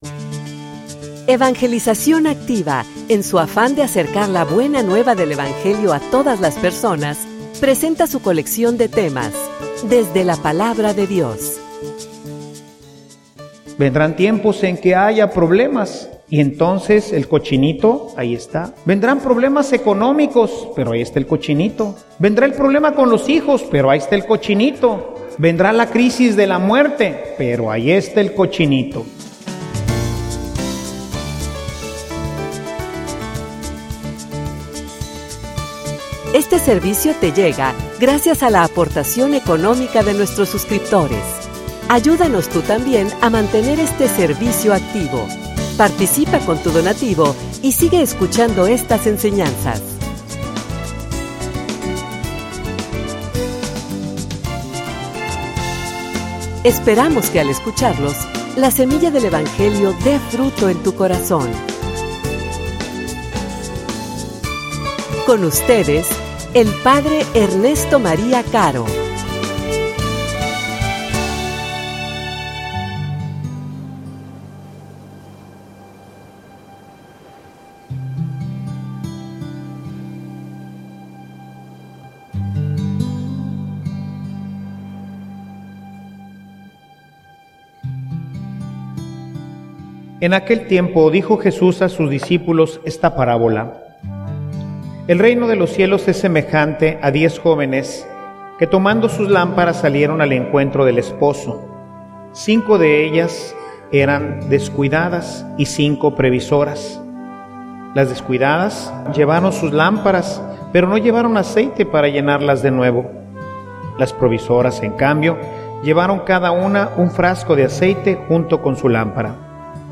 homilia_Manten_tu_lampara_con_aceite.mp3